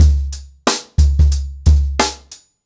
TrackBack-90BPM.73.wav